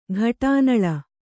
♪ ghaṭānaḷa